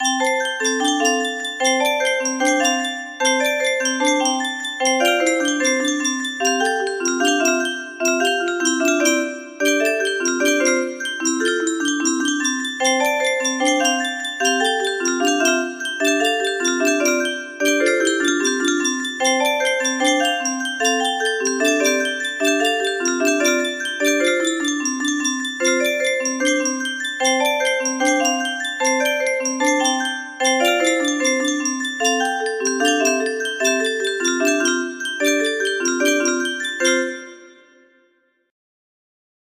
Luc box v.2 music box melody